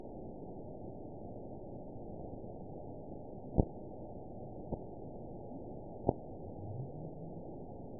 event 912121 date 03/18/22 time 15:54:47 GMT (3 years, 1 month ago) score 7.18 location TSS-AB09 detected by nrw target species NRW annotations +NRW Spectrogram: Frequency (kHz) vs. Time (s) audio not available .wav